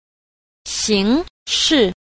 8. 形式 – xíngshì – hình thức